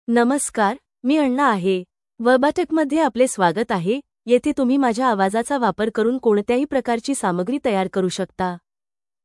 Anna — Female Marathi AI voice
Voice: AnnaGender: FemaleLanguage: Marathi (India)ID: anna-mr-in
Voice sample
Listen to Anna's female Marathi voice.
Anna delivers clear pronunciation with authentic India Marathi intonation, making your content sound professionally produced.